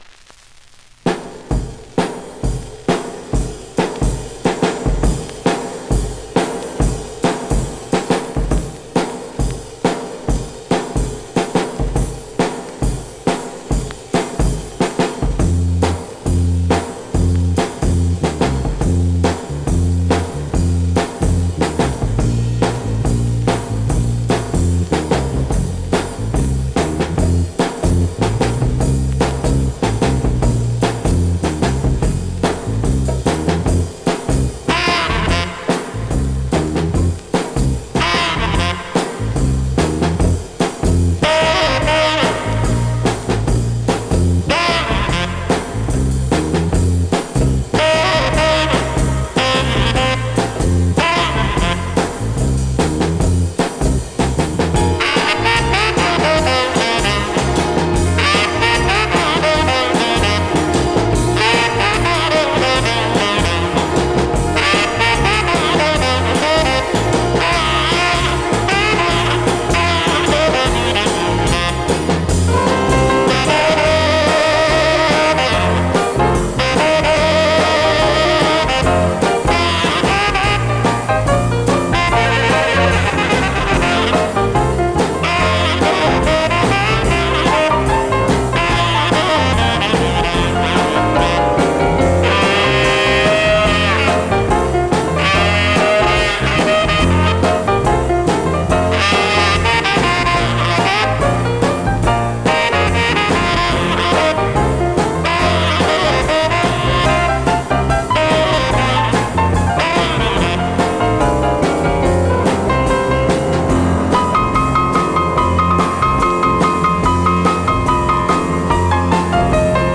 Vinyl has a few light marks plays great .
Great classic mid-tempo Rnb / Mod Instro dancer .
R&B, MOD, POPCORN